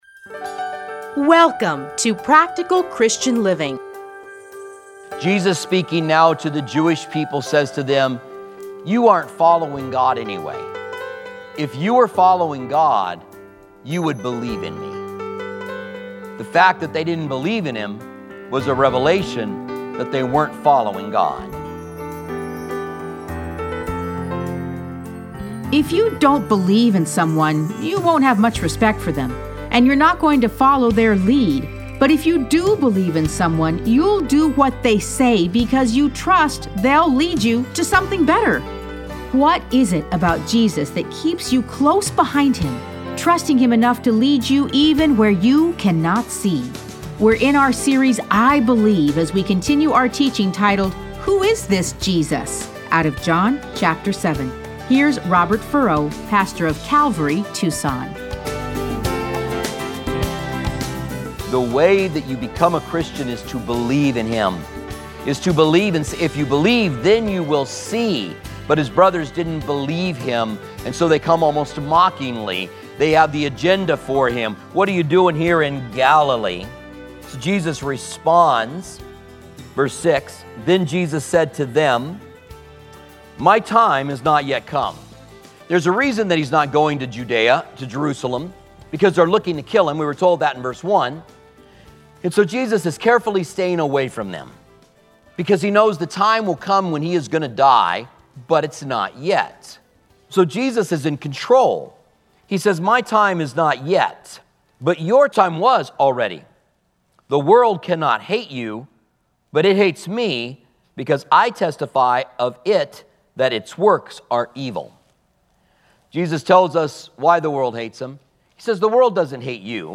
Listen to a teaching from John 7:1-31.